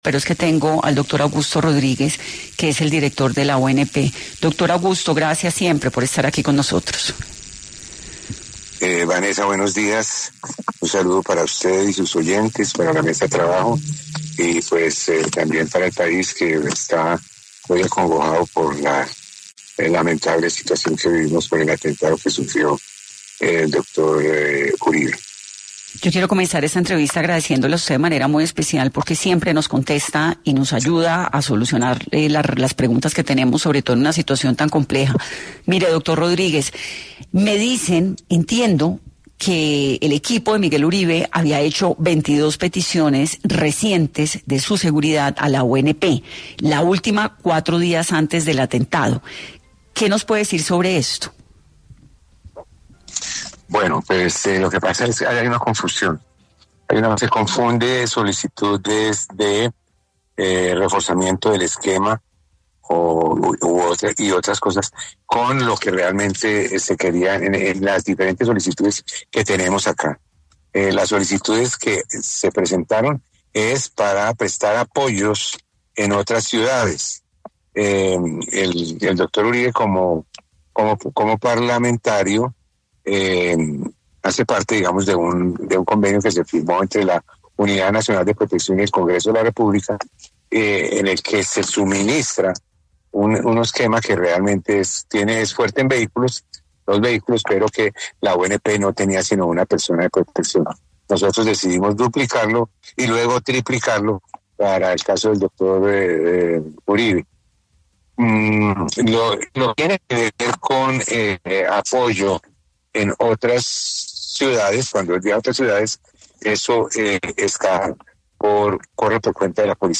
Augusto Rodríguez, director de la Unidad Nacional de Protección (UNP) contó en 10AM porque ese día el precandidato presidencial no contaba con su esquema de seguridad completo.